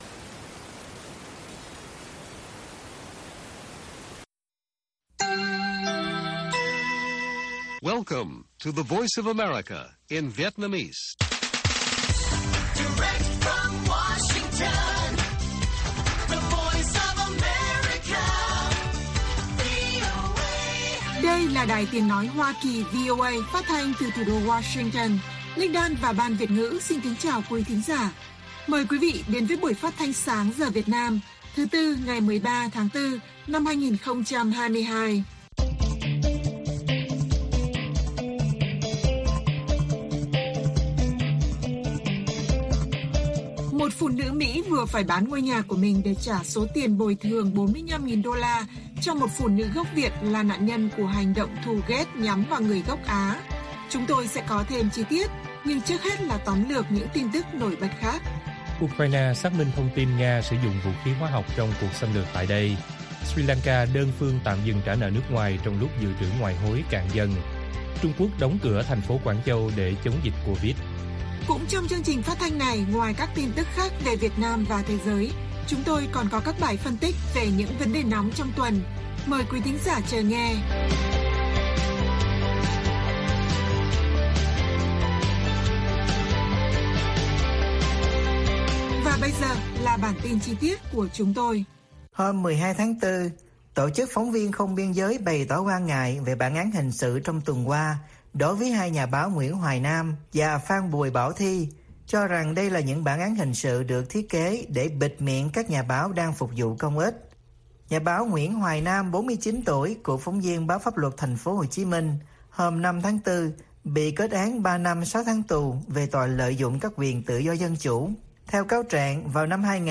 Ukraine xác minh tin Nga sử dụng vũ khí hóa học - Bản tin VOA